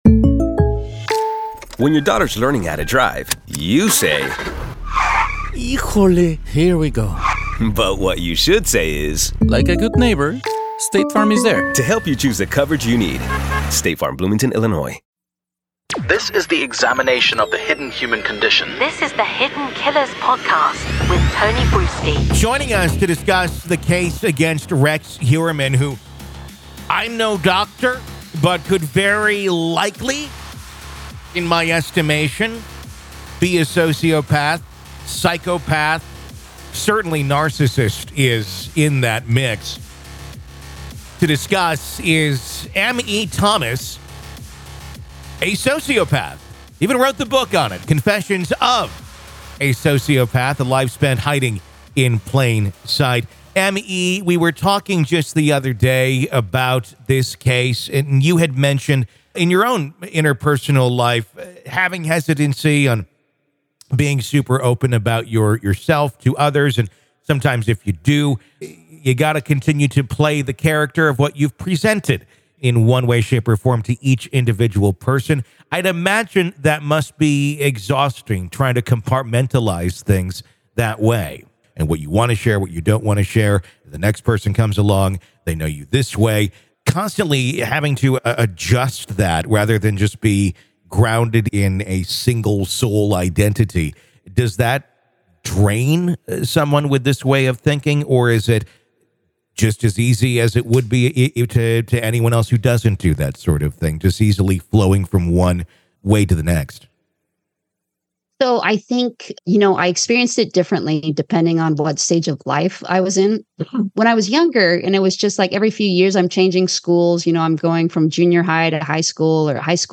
Conversation With a Psychopath About the Mind Of Rex Heuermann